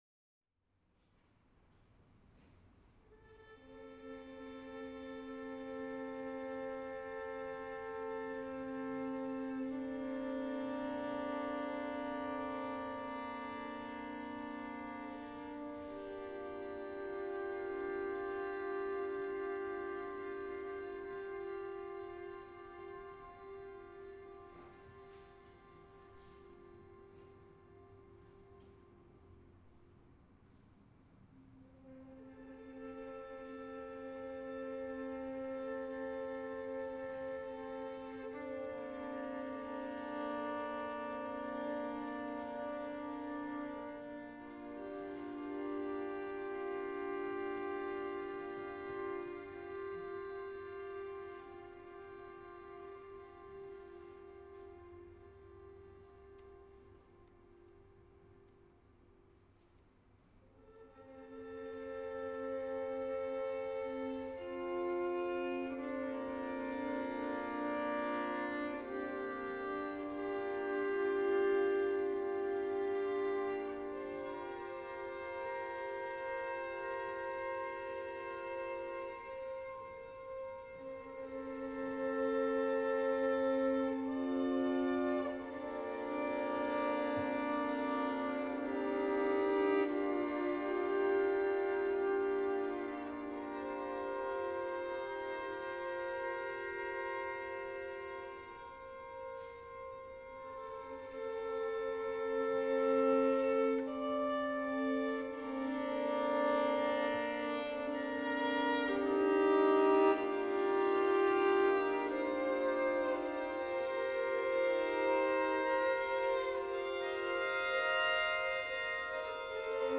Genre electroacoustic
Instrumentation violin with delays
Recording type live
Musical styles and elements modern, expressive
The electronic part consist of four mono-delays